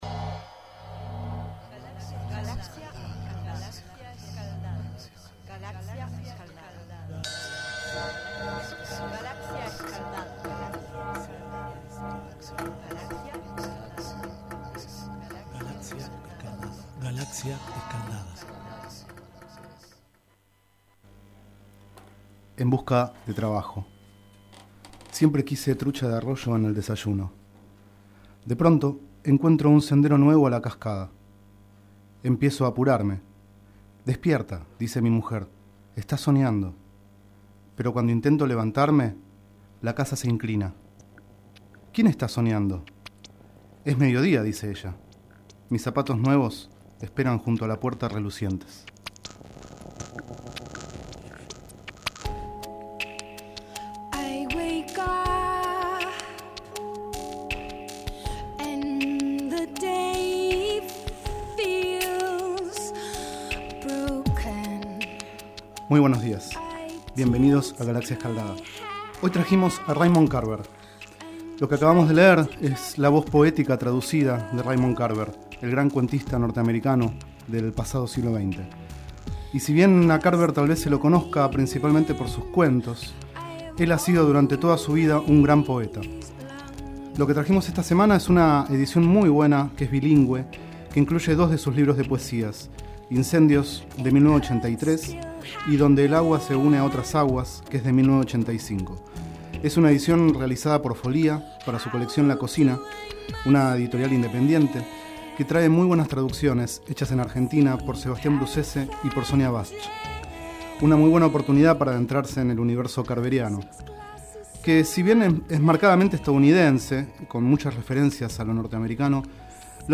29º micro radial, realizado el 1º de octubre de 2011, sobre el libro Incendios + Donde el agua se une a otras aguas, de Raymond Carver.